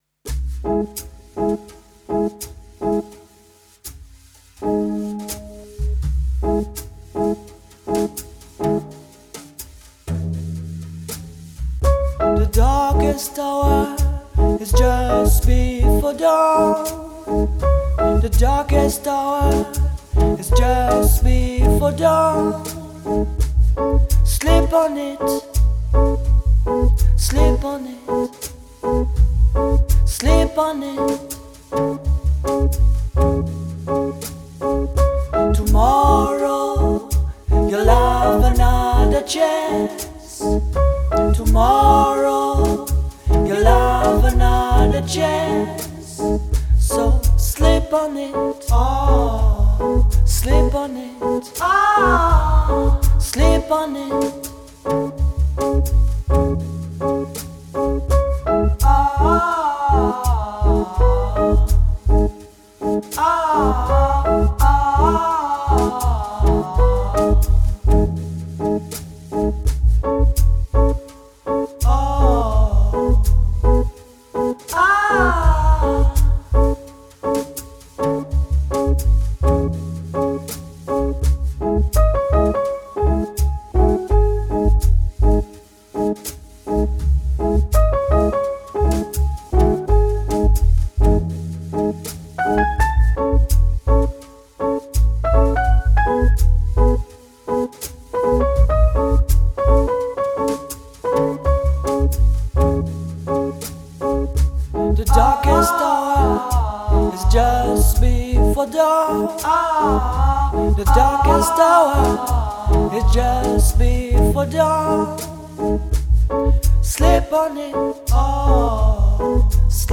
Жанр: Dub.